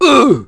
Oddy-Vox_Damage_02.wav